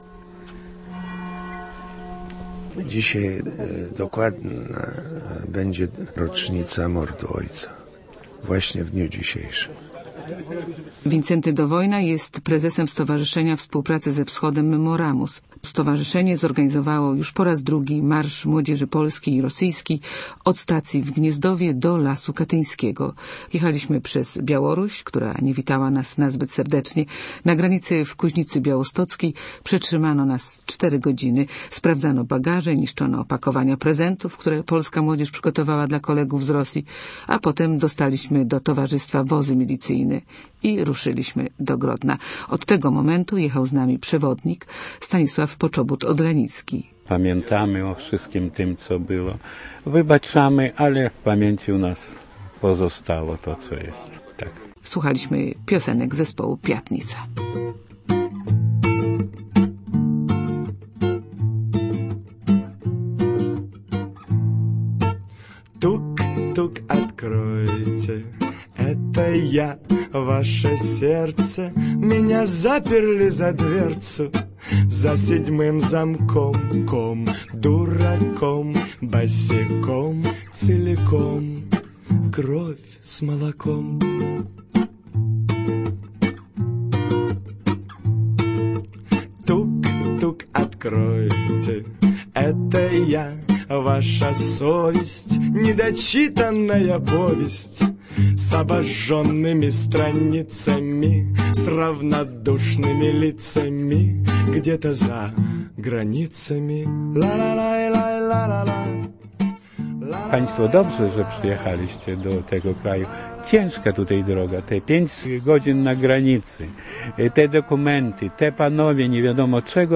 x3gj7hdyao7fy30_katyn_dwie_narracje_reportaz.mp3